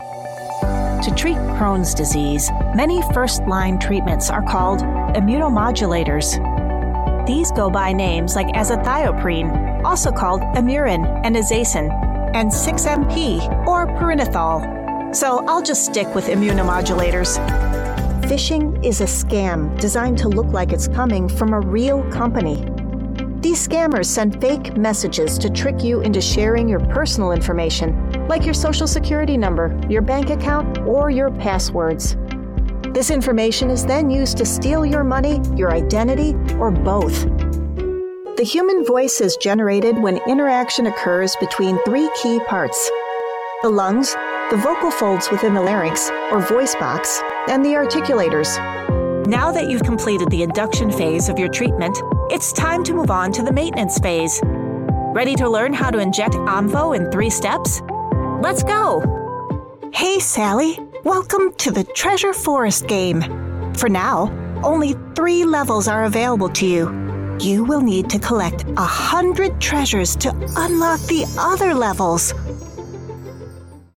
Explainer & Whiteboard Video Voice Overs
Adult (30-50) | Yng Adult (18-29)